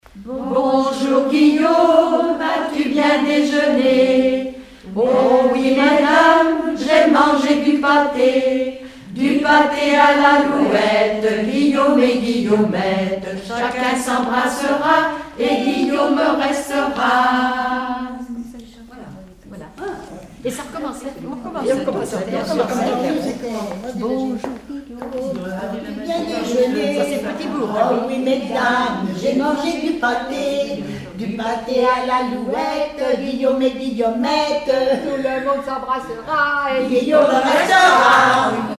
- 007913 Thème : 0079 - L'enfance - Rondes enfantines à baisers ou mariages Résumé : Mon grand Guillaume, as-tu bien déjeuné ?
Regroupement de chanteurs du canton
Pièce musicale inédite